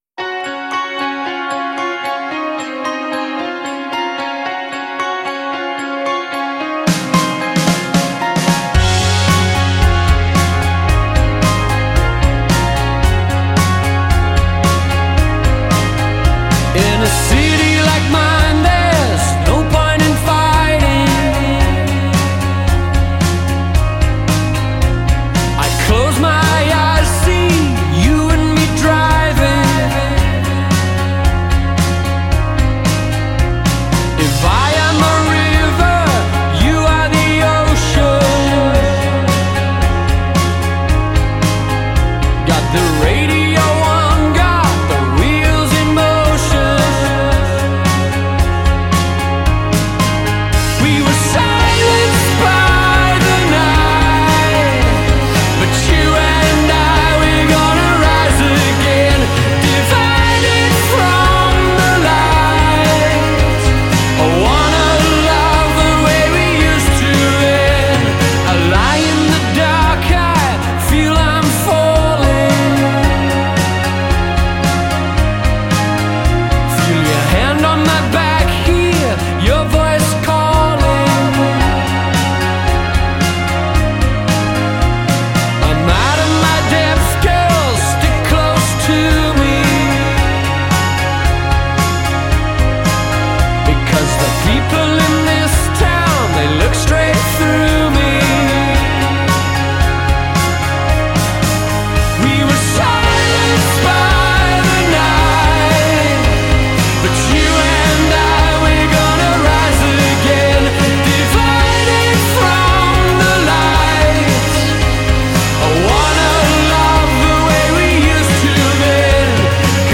Wistful…epic…widescreen…grandiose.